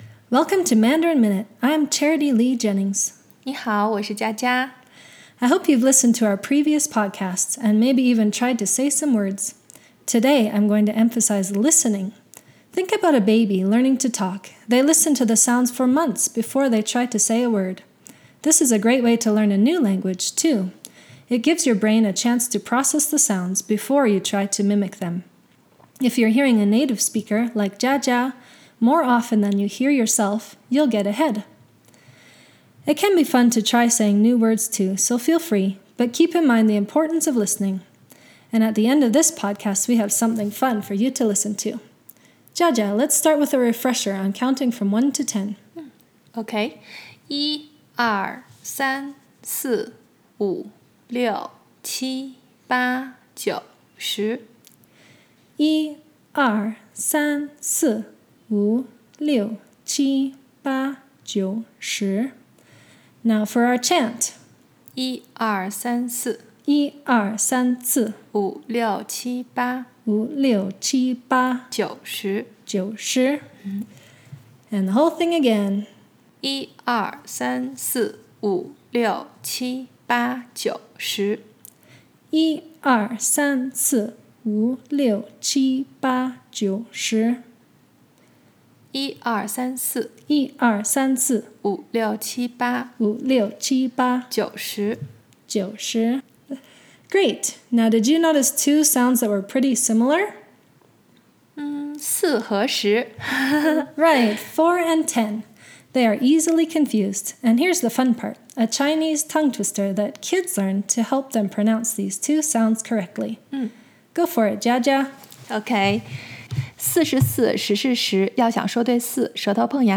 we chant from one to ten, with a twist!